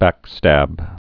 (băkstăb)